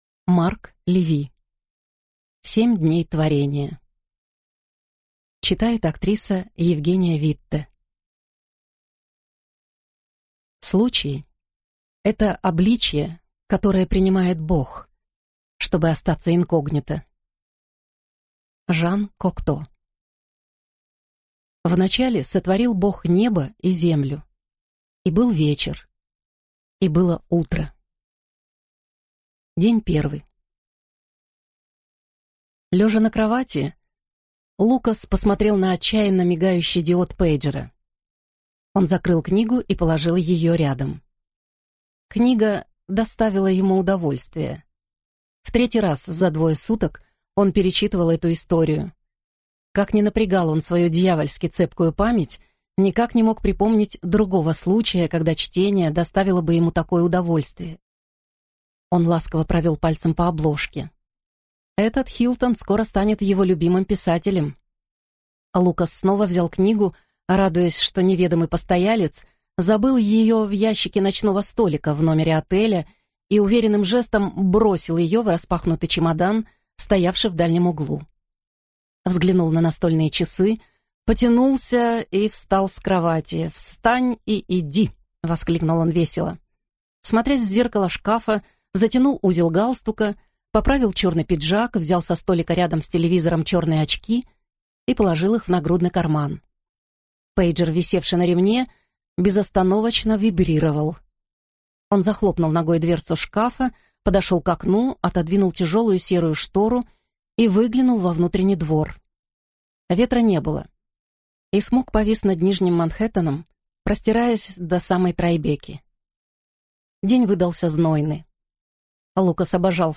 Аудиокнига Семь дней творения | Библиотека аудиокниг